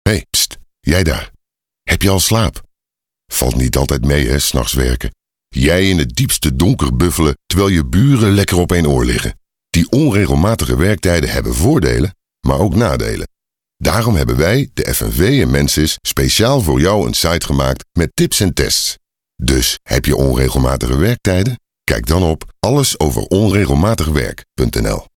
Deep, warm & trustworthy experienced voice over with impact.
Sprechprobe: Sonstiges (Muttersprache):